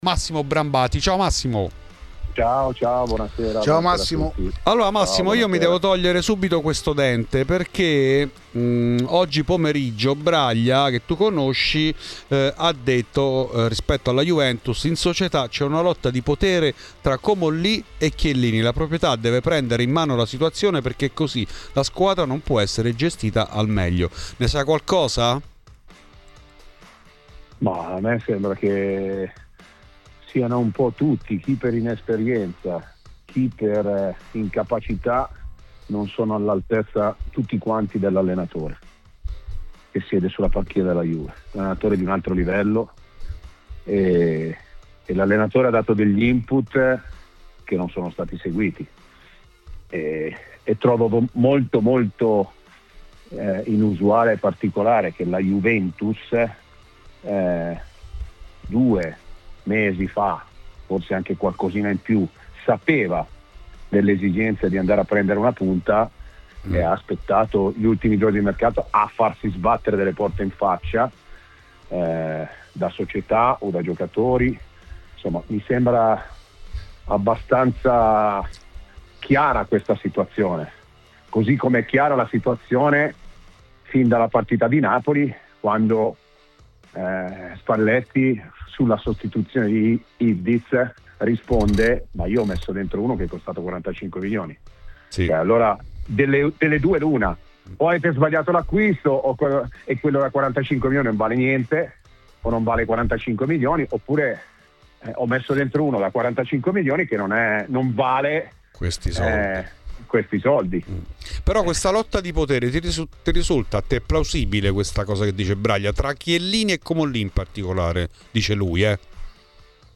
Ospite di Radio Bianconera , durante Fuori di Juve , è stato l'ex calciatore e procuratore